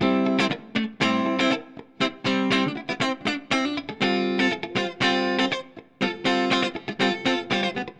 29 Guitar PT3.wav